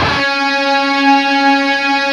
LEAD C#3 LP.wav